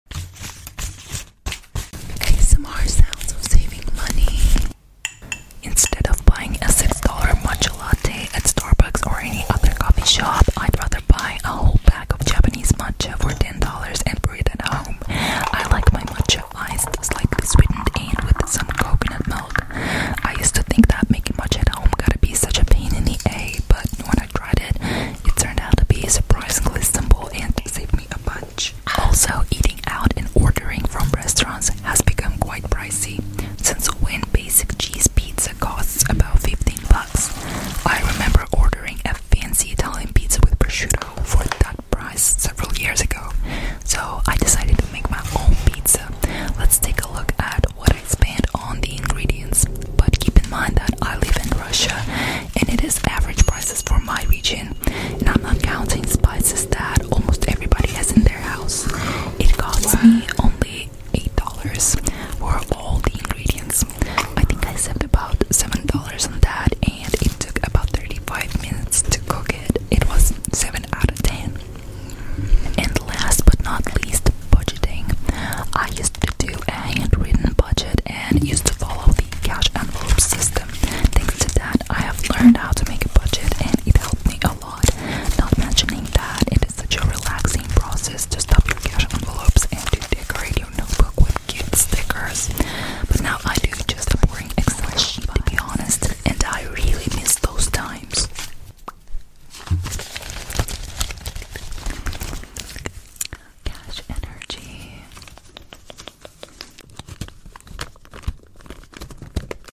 ASMR sounds of saving money sound effects free download